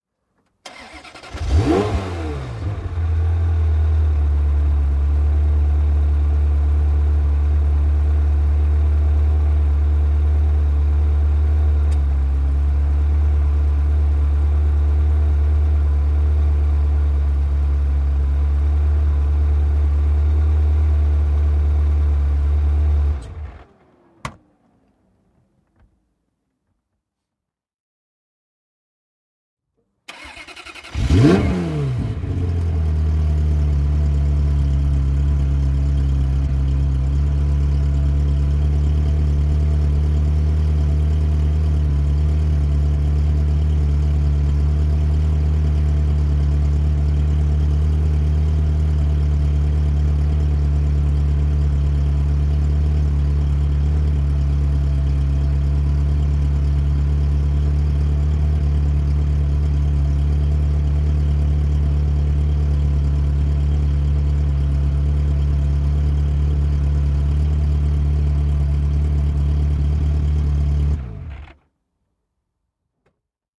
Ferrari 355 GTB 1995 заводится, работает на холостых, глохнет, затем снова заводится